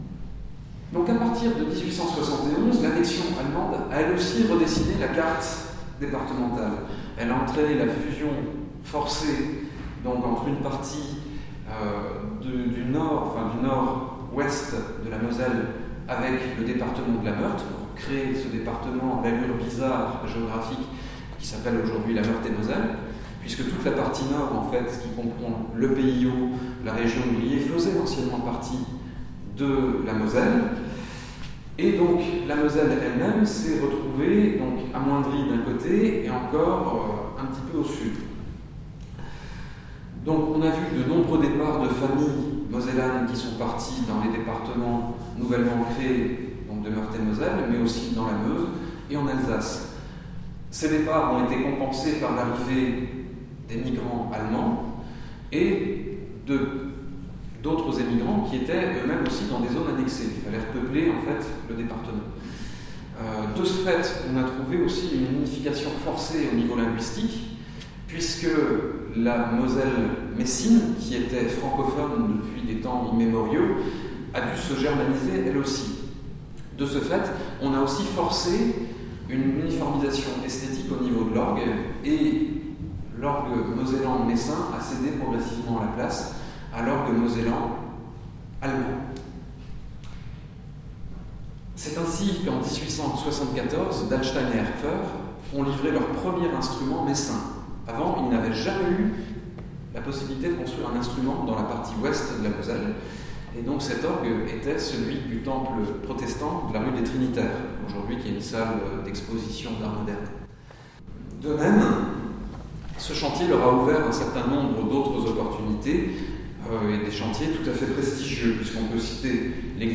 Extraits de la conférence